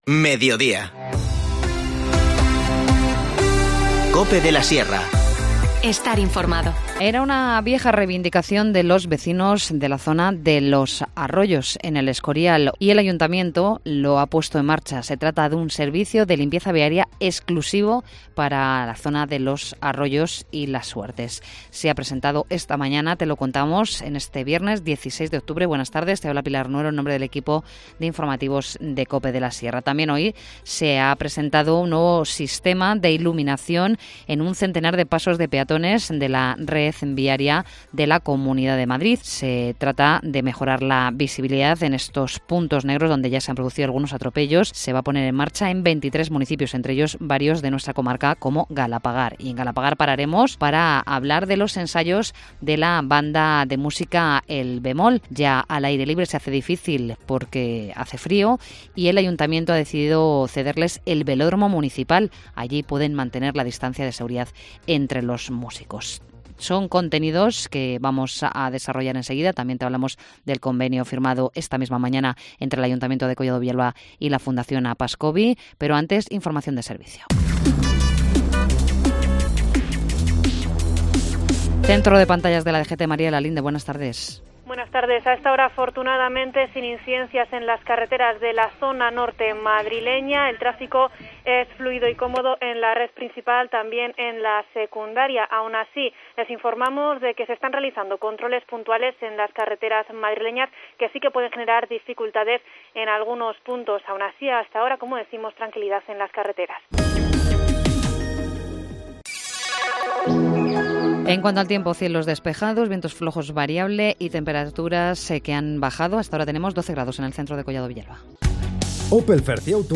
Informativo Mediodía 16 octubre